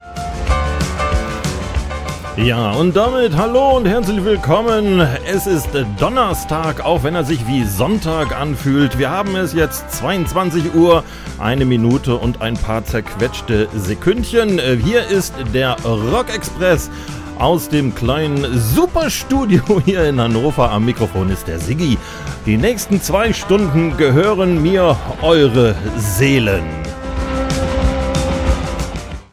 und ein AirCheck (Auszug; aus Größengründen reduziert auf 128 kbps) belegt, dass die Moderation doch funktioniert.
Der Fade zu Beginn und Ende Deiner Moderation, hast Du den wirklich auf dem Mischpult gefahren?
Zumal mir die Musik bei der Moderation als Bett / Hintergrund immer noch zu laut ist.
Im Blindtest hätte ich jetzt auf einen “Talk Over-Button” mit zu geringer Absenkung getippt.